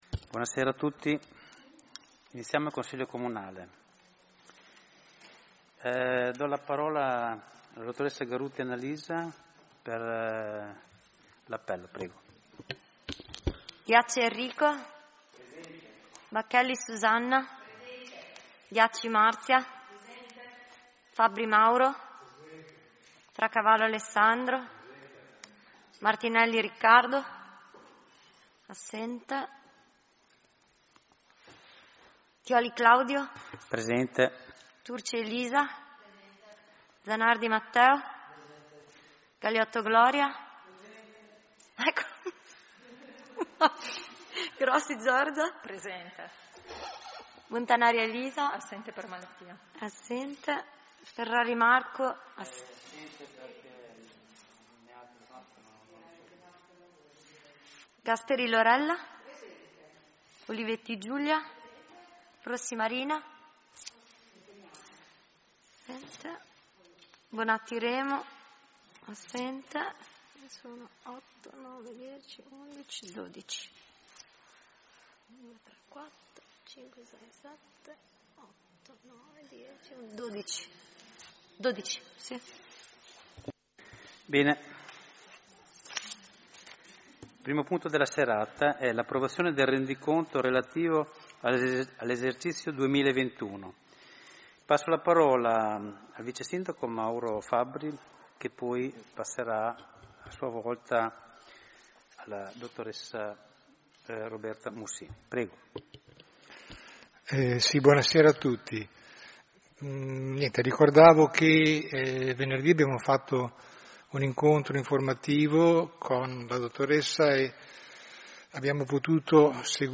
Seduta del 28/04/2022